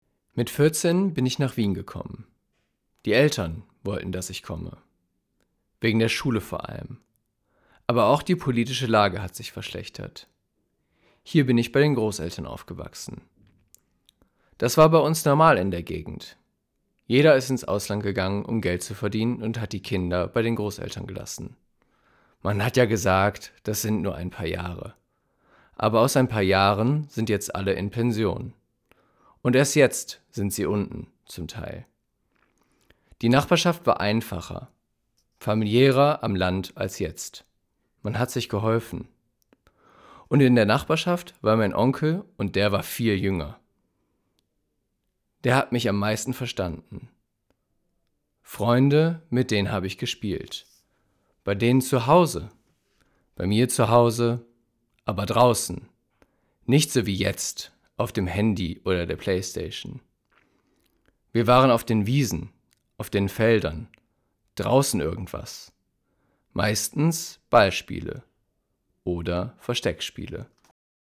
Für die Website wurden die Textstellen nachgelesen: Einige von Jugendlichen und jungen Erwachsenen aus den Wohnhausanlagen, andere von Mitgliedern des Projektteams oder ausgebildeten Schauspielern.